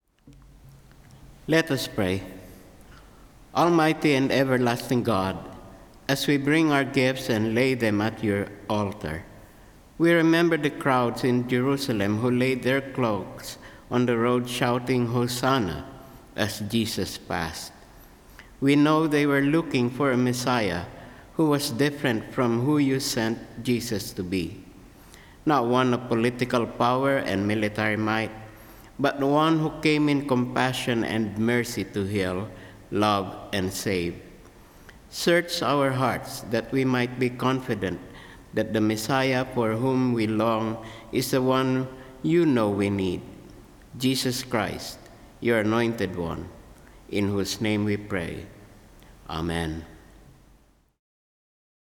Service of Worship
Offertory Prayer